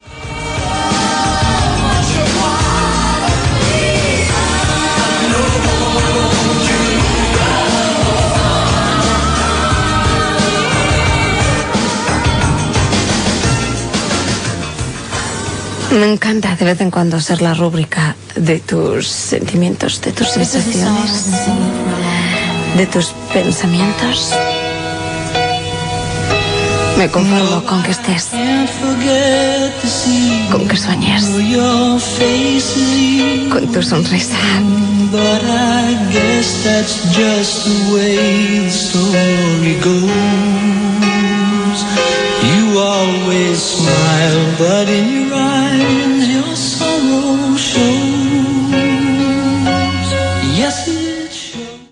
Tema musical, comentari i tema musical
Musical